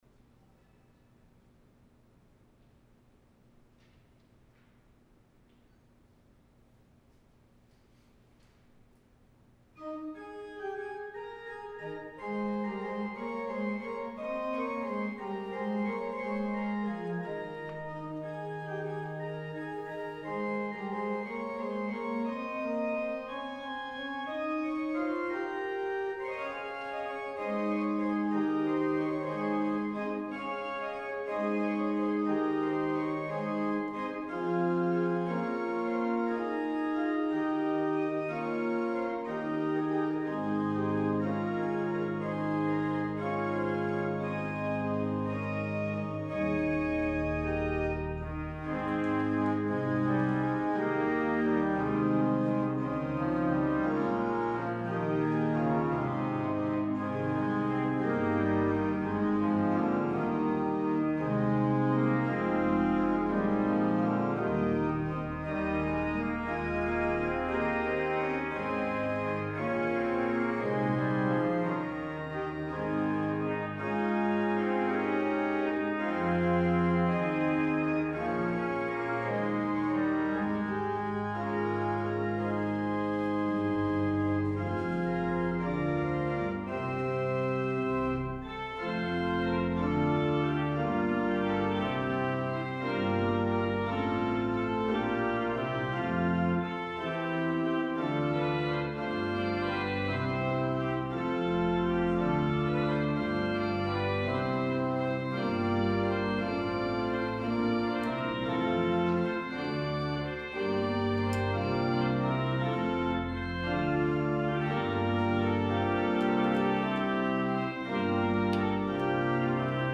Audio only for Sunday Worship 11-29-20